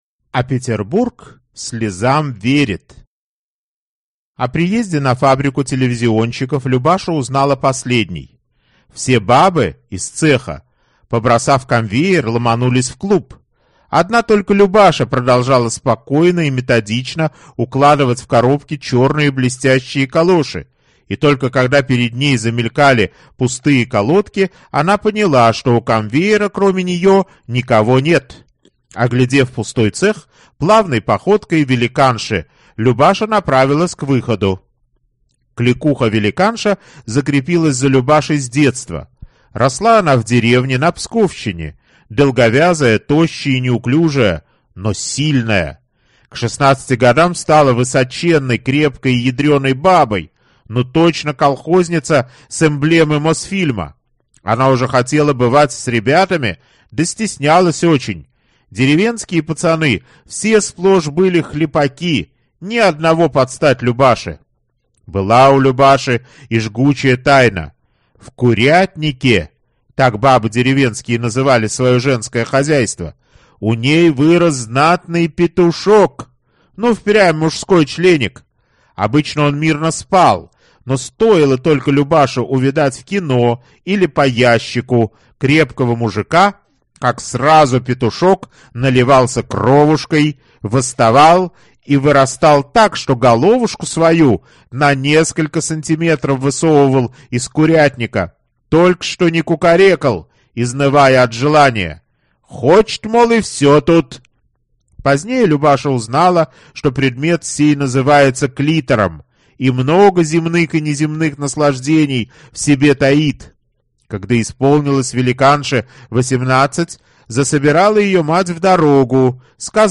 Аудиокнига Чудо-поликлиника | Библиотека аудиокниг